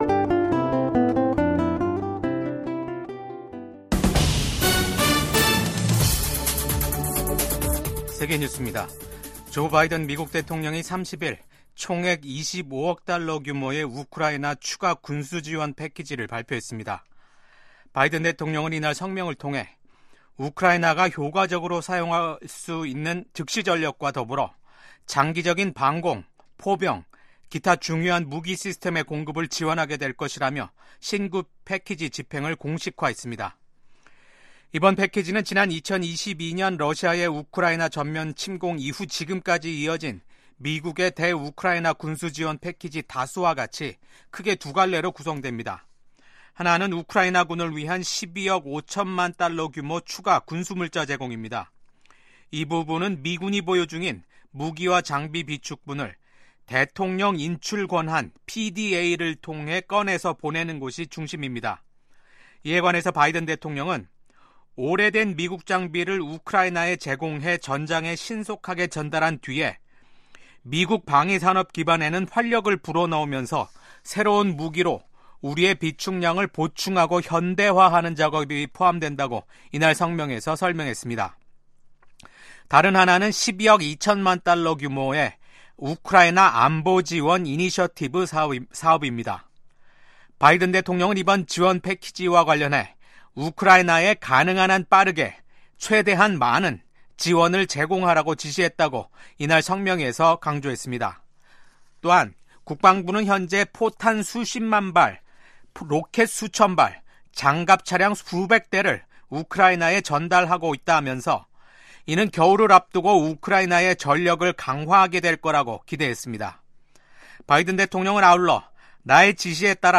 VOA 한국어 아침 뉴스 프로그램 '워싱턴 뉴스 광장'입니다. 김정은 북한 국무위원장은 연말 노동당 전원회의에서 미국에 대해 최강경 대응전략을 천명했지만 구체적인 내용은 밝히지 않았습니다. 러시아에 파병된 북한군이 무모한 인해전술을 펼치면서 지난주에만 1천명이 넘는 사상자가 발생했다고 미국 백악관이 밝혔습니다. 미국 국무부는 한국의 새 대통령 대행과도 협력할 준비가 돼 있다고 밝혔습니다.